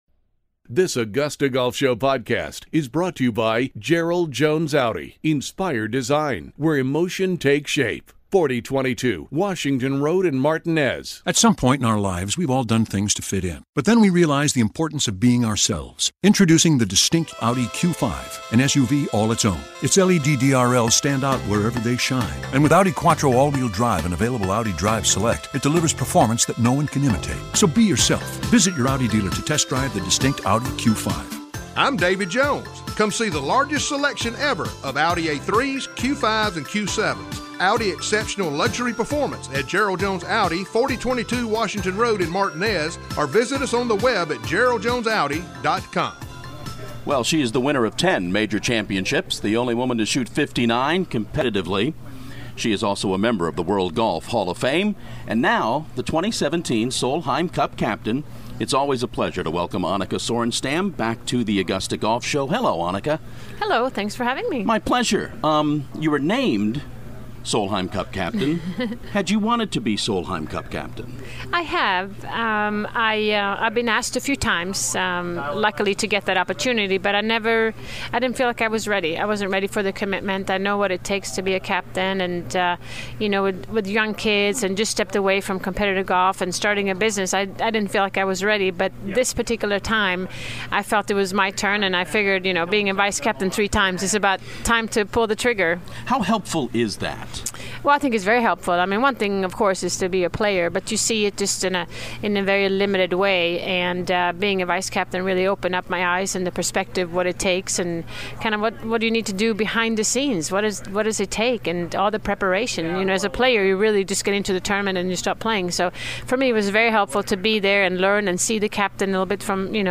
World Golf Hall of Fame member Annika Sorenstam stops by the media center at Augusta National to talk about her selection as 2017 Solheim Cup captain and more